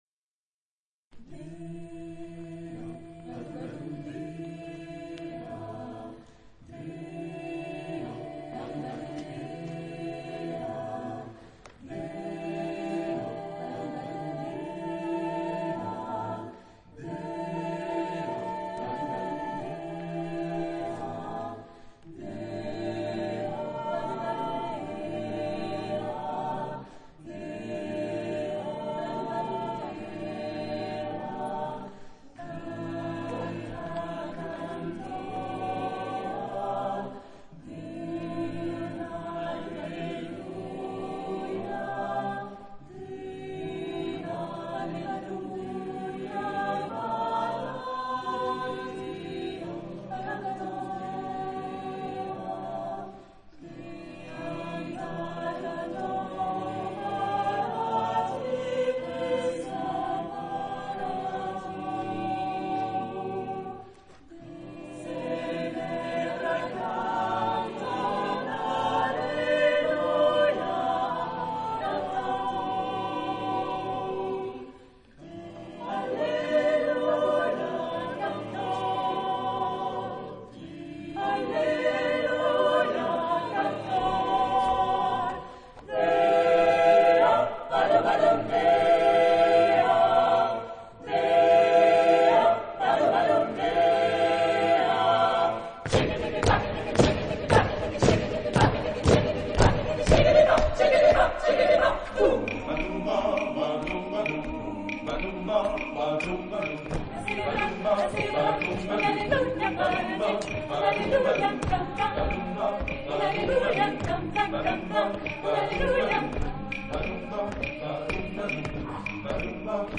Género/Estilo/Forma: canción litúrgica
Carácter de la pieza : alegre
Tipo de formación coral: SSAATTBB  (8 voces Coro mixto )